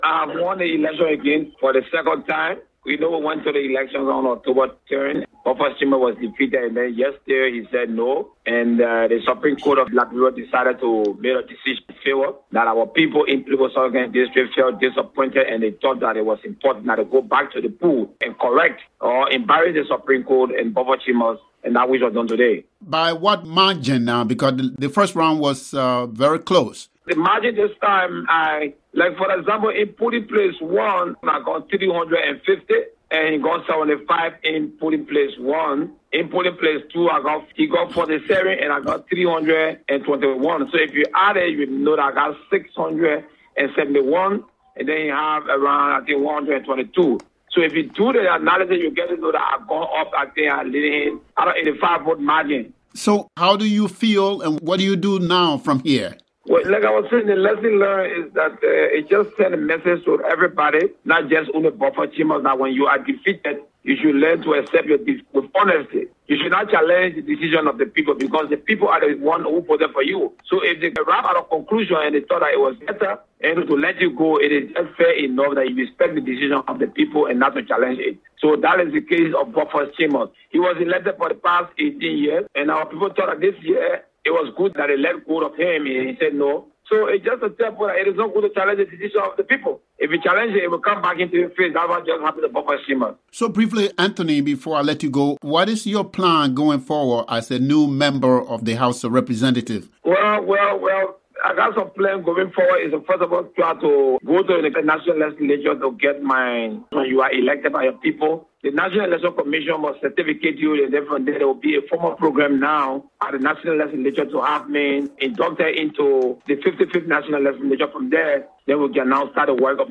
Williams called VOA Thursday evening to say he had defeated the former speaker for the second time.